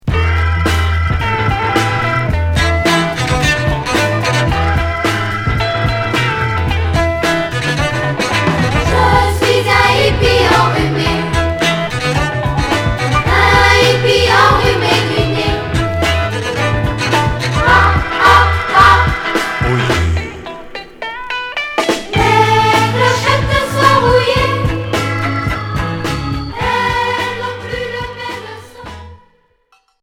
Groove enfantin